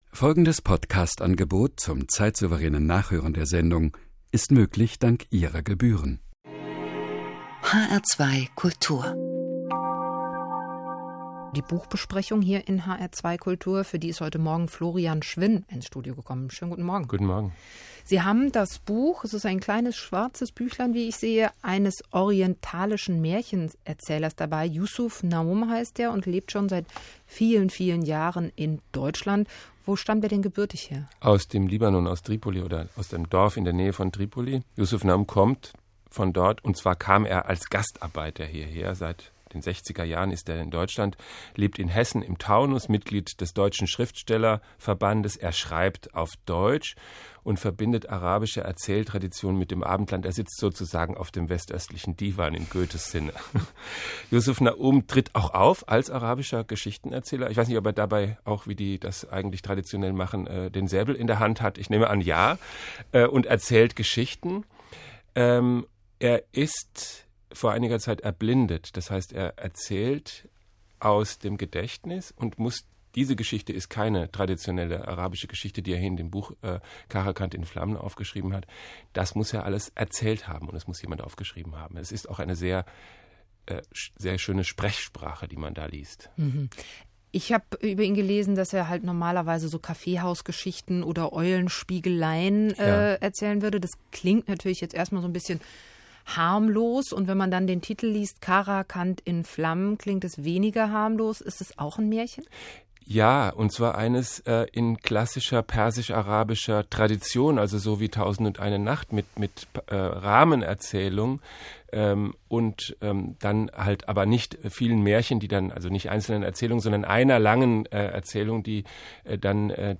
Buchbesprechung im Hessischen Rundfunk HR 2 Mikado am 14.2.2011.